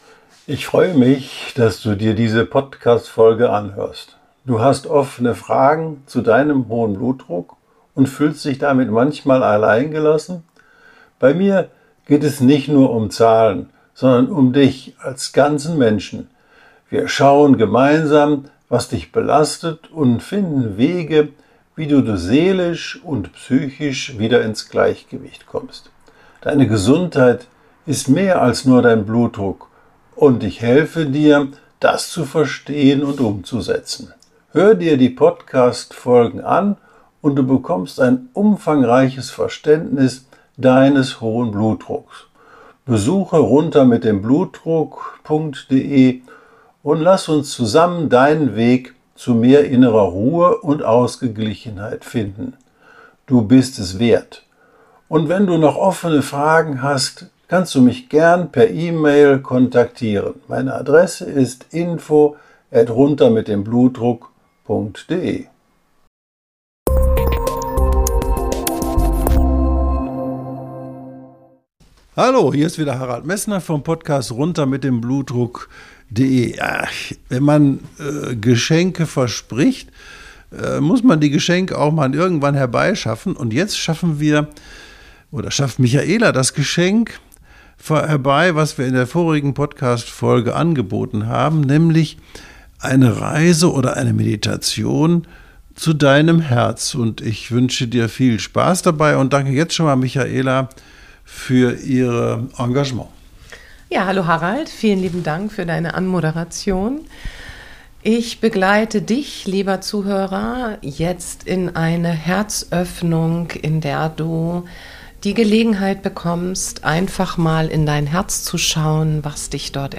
Herzmeditation
Tauche hier ein in die Meditation: